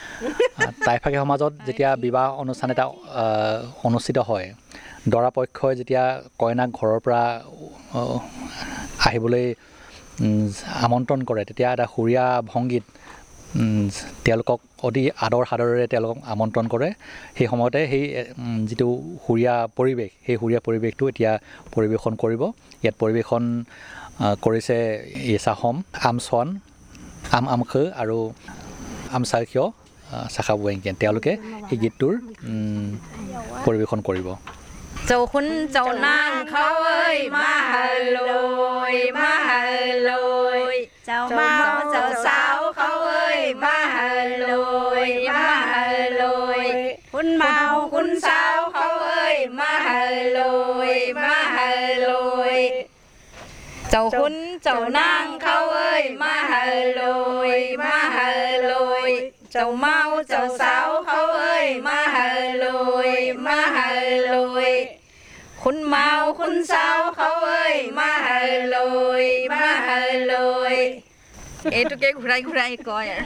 Performance of a group song about marriage and related